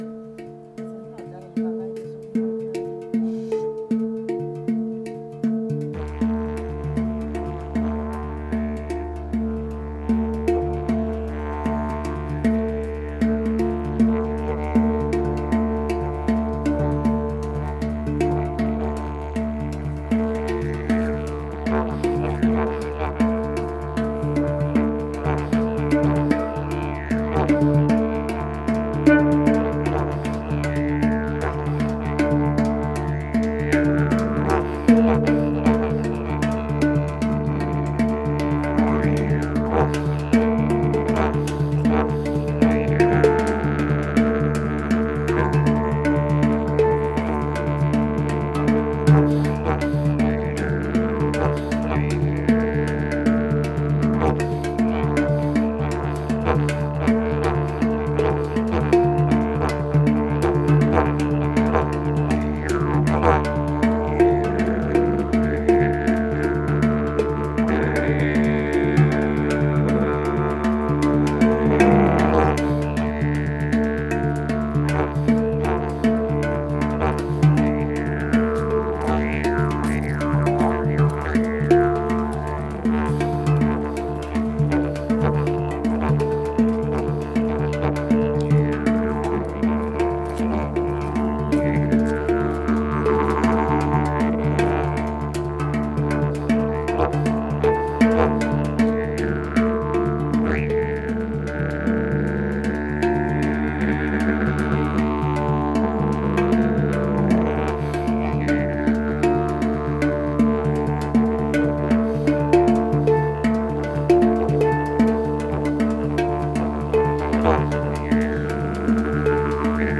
improvisations with didgeridoo, handpan, theremin & voices
For the occasion we improvised on handpan, didgeridoo and theremin and used our voices at times too. The handpan, didgeridoo and voices were unamplified, for my theremin I had combined the Fender Greta (modified with lower gain pre-amp and phase-inverter tubes) with '50s vintage loudspeaker cabinet and a Vox Pathfinder 15 EXR (a limited edition with 10" instead of 8" loudspeaker).
I did record some parts of our playing with a Zoom H2n, and postprocessed the recordings and uploaded them yesterday.
The interaction with theremin sounds very harmonic in case of coincidence of that tones or one of their overtones.